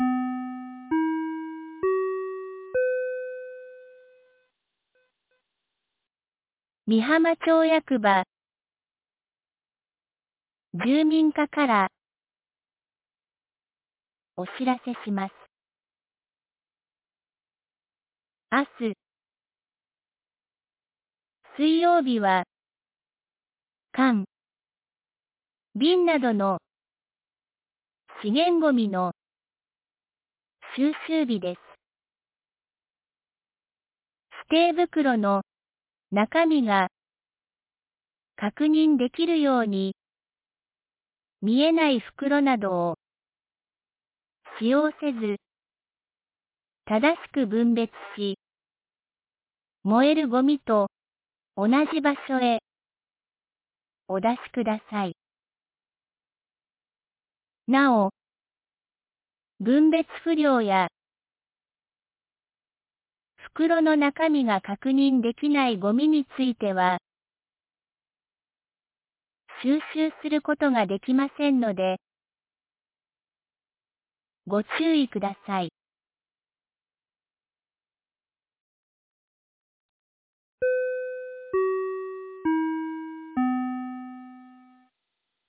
2026年02月24日 18時46分に、美浜町より全地区へ放送がありました。